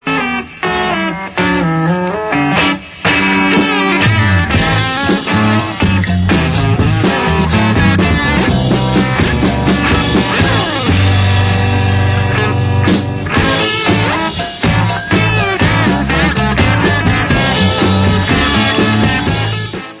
LOST CLASSIC OF 60'S UK ACID FOLK/BAROQUE PSYCHEDELICA